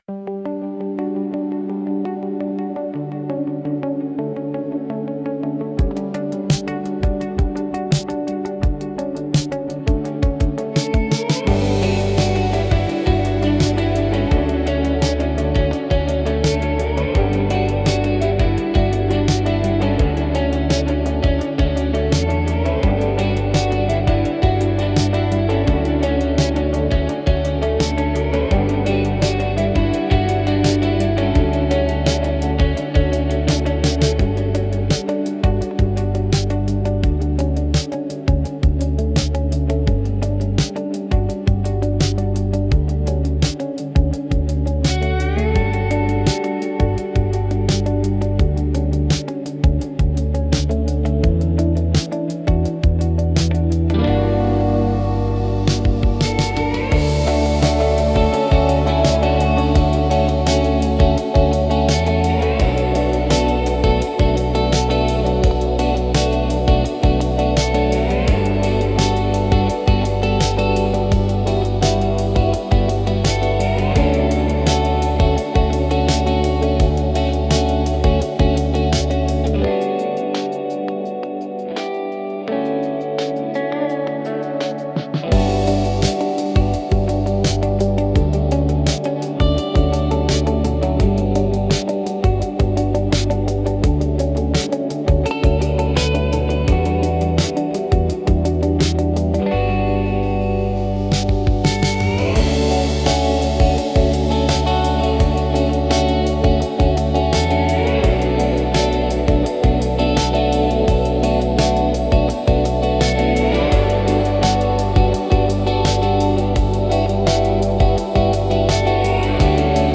静かで美しい音の重なりが心地よく、感性を刺激しながらも邪魔にならない背景音楽として活用されます。